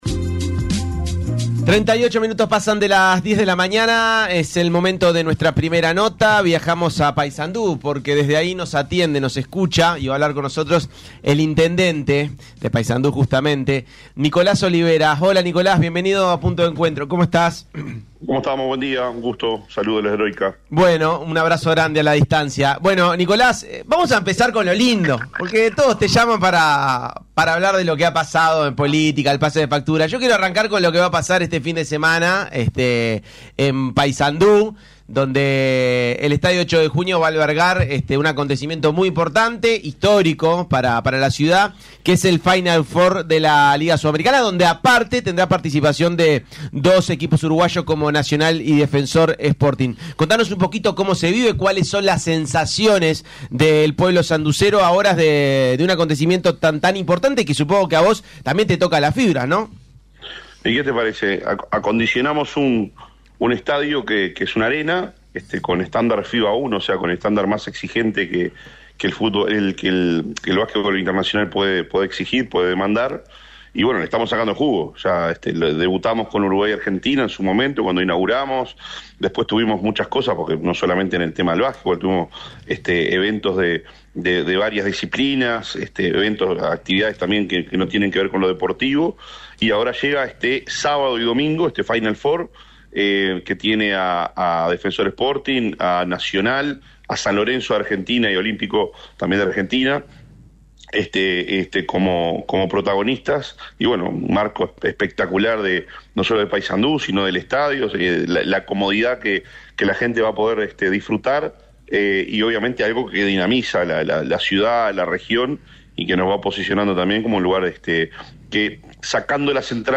Entrevista a Nicolás Olivera (Intendente de Paysandú)